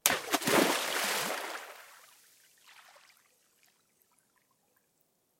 描述：使用大胆记录使用Tascam07MKIIEdited录制。 记录用金属叉溅出少量水，然后用大胆编辑，改变音高和包络，以达到所需的声音
标签： 撇渣 卵石 飞溅 水花 扑通 滴剂 石头
声道立体声